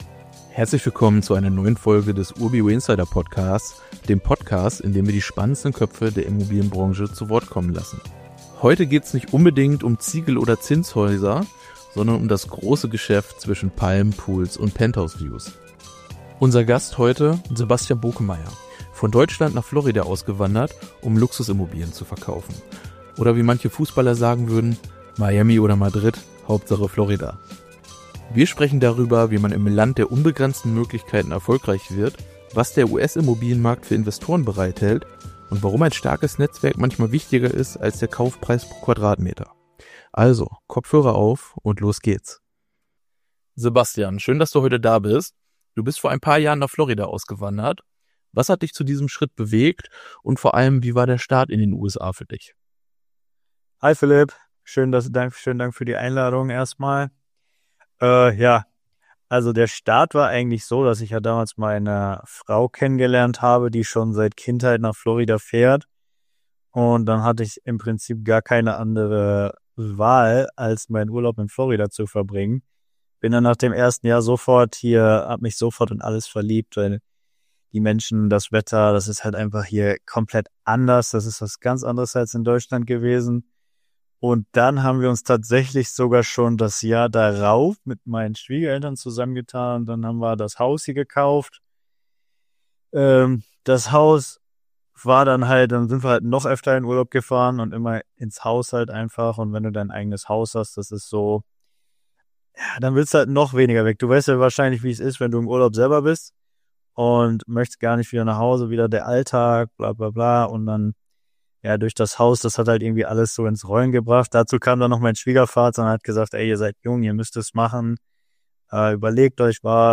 Im Gespräch geht es um die Marktlage und die Funktionsweise des amerikanischen Immobilienmarktes und warum das alte Sprichtwort gilt: 90% of success is just showing up.